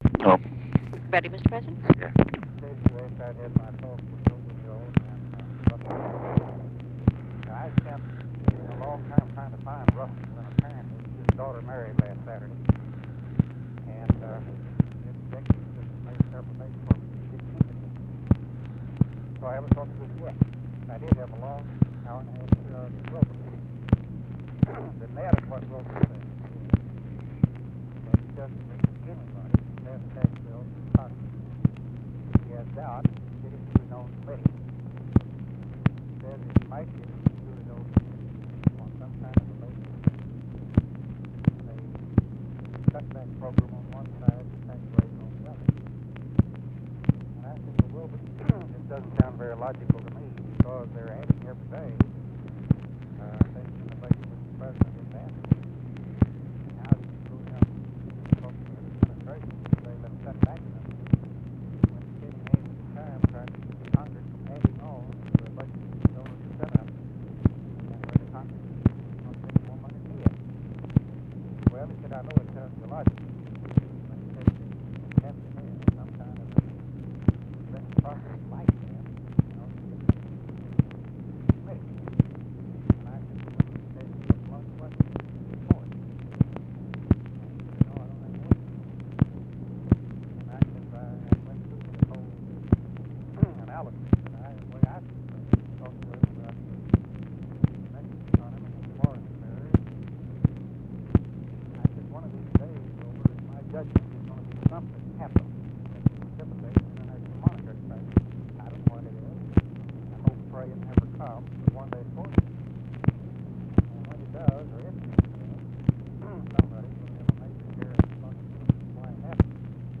Conversation with ROBERT ANDERSON, June 27, 1966
Secret White House Tapes